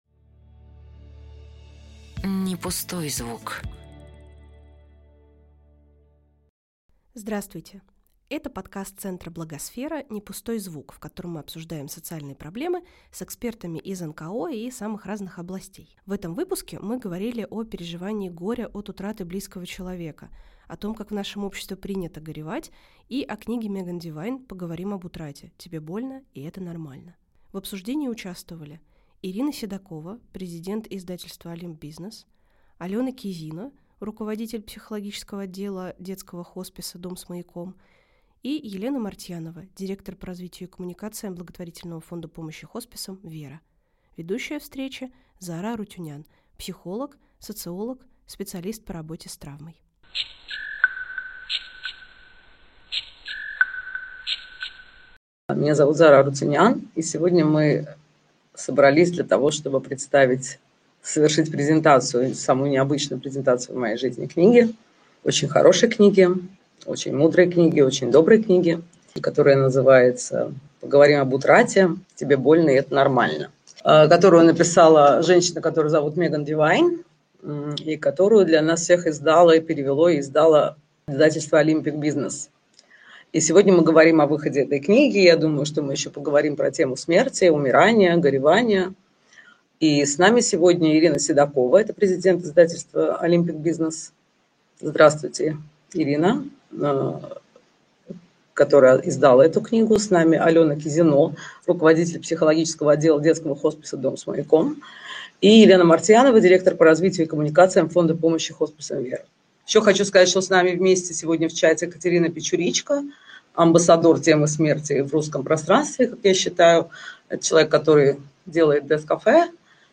Этот подкаст мы смонтировали по мотивам встречи книжного клуба Благосферы.